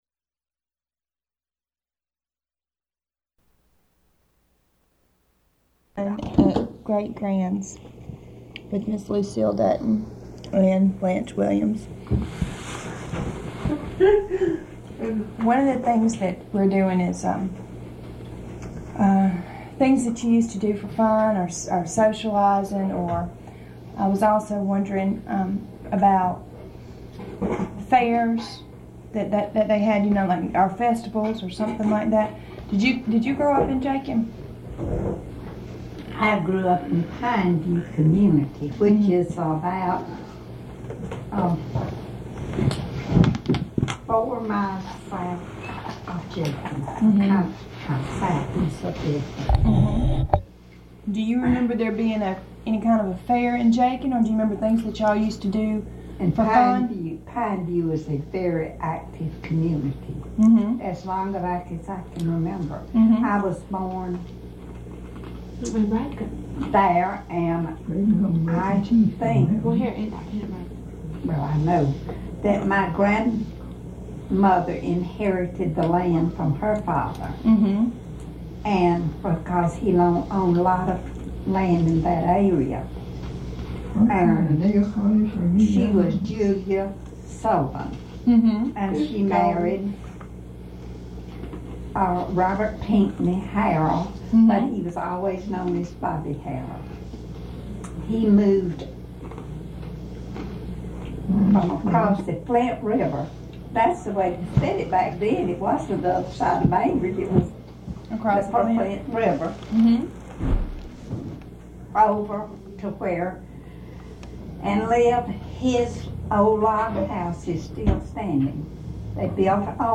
Interview
From the South Georgia Folklife Collection at Valdosta State University Archives and Special Collections.